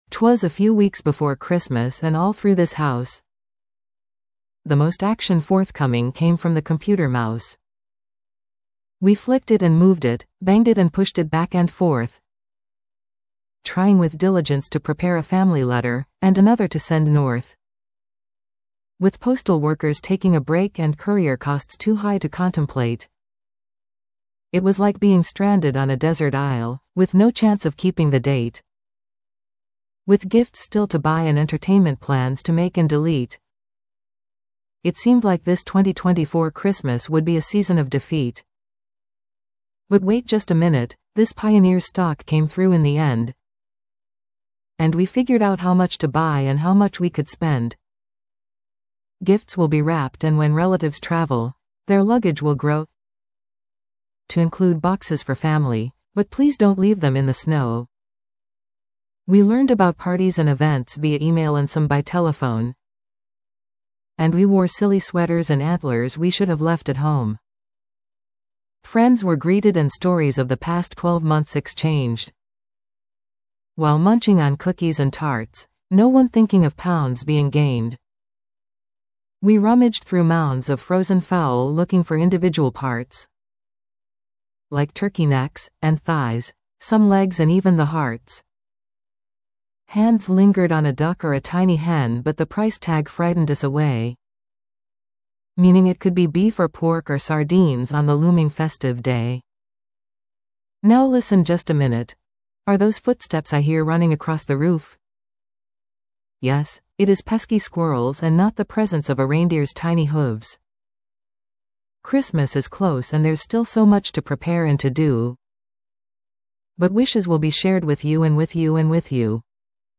Semi-poetic performance offers sincere wishes for an enjoyable holiday season.